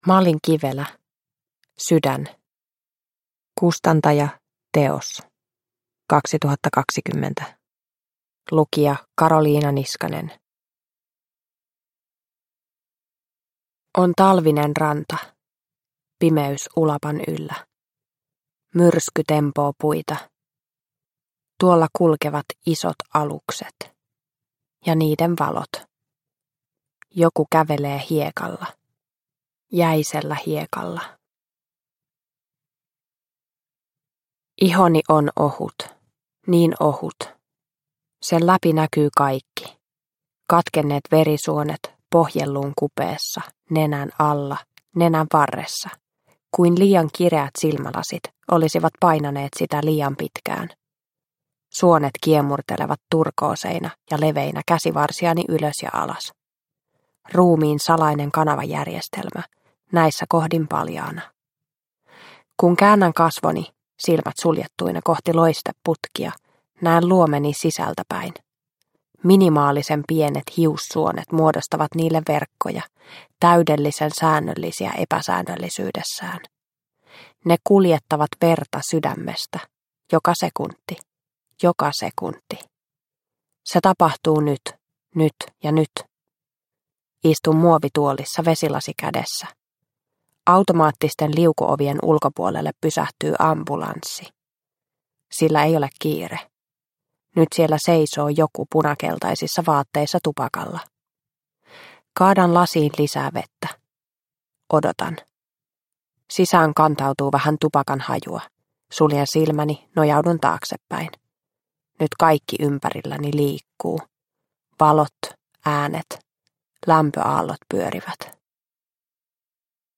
Sydän – Ljudbok – Laddas ner